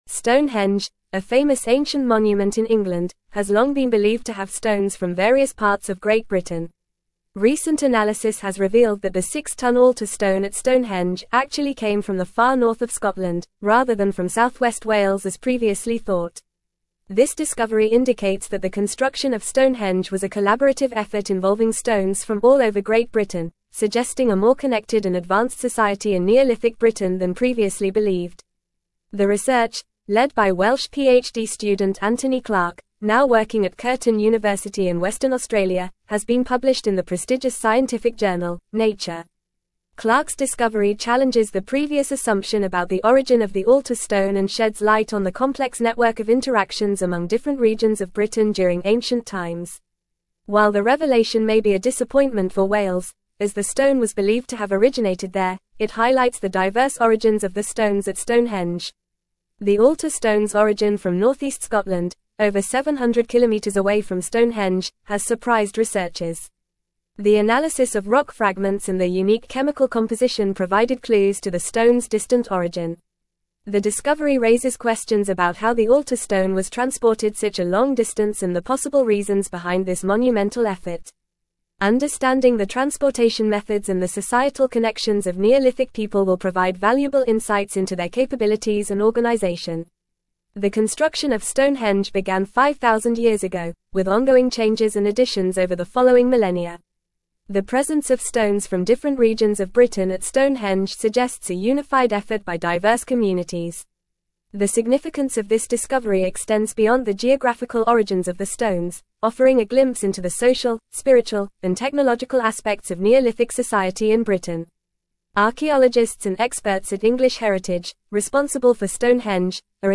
Fast
English-Newsroom-Advanced-FAST-Reading-Stonehenge-Altar-Stone-Originates-from-Scotland-Not-Wales.mp3